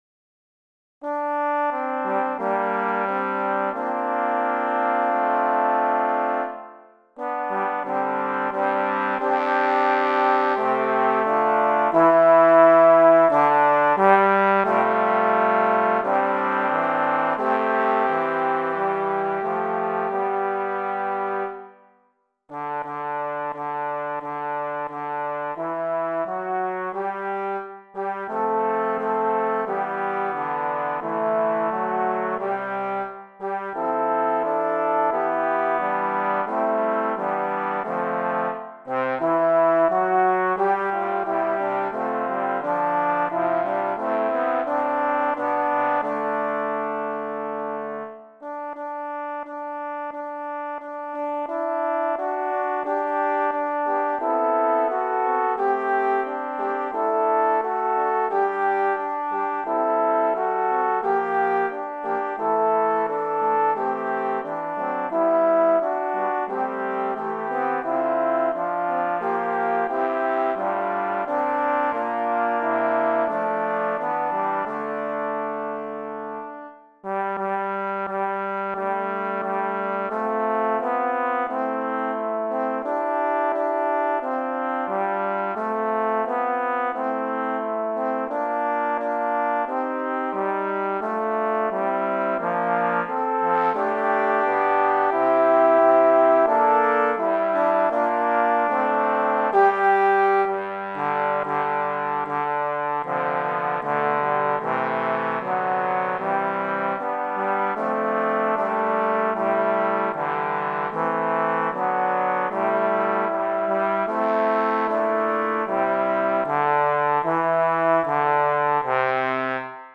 Voicing: Trombone Quartet